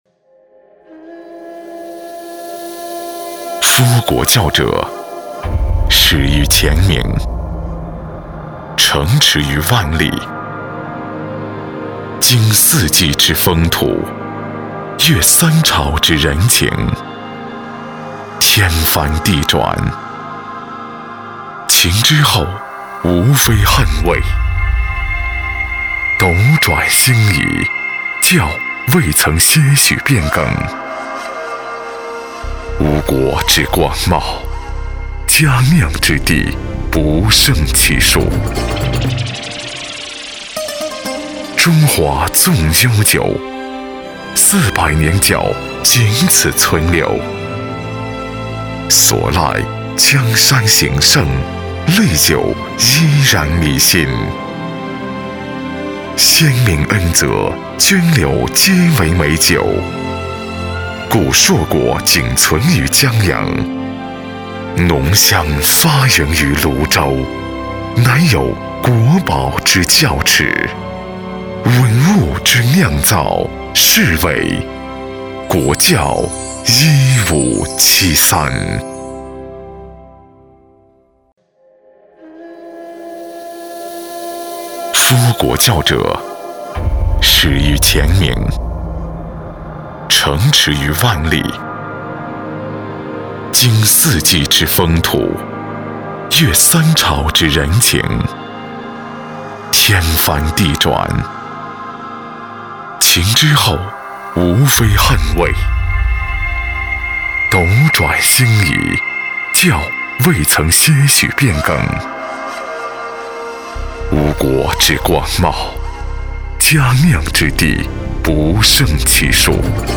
• 男S355 国语 男声 广告-国窖一五七三赋-广告宣传-深沉大气 大气浑厚磁性|沉稳|科技感|积极向上|时尚活力